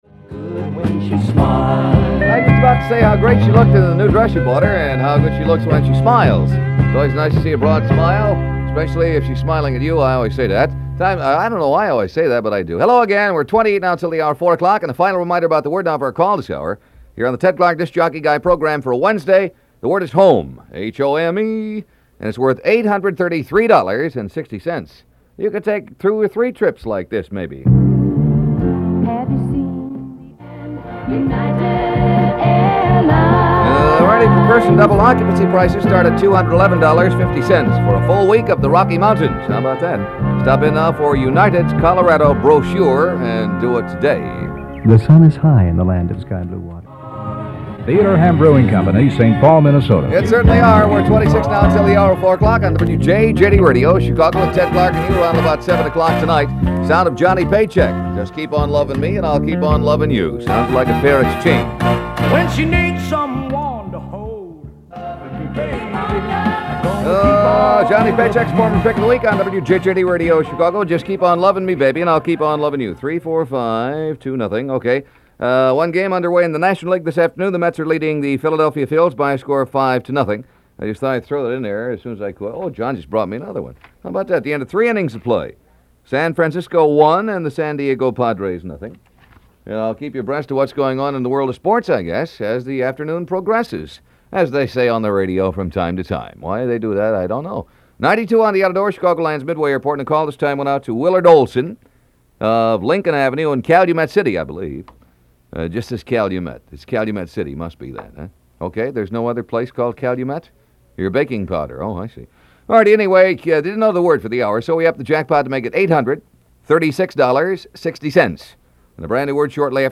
The quintessential mid-day pro. Perfect pipes. Smooth execution. Friendly vibe.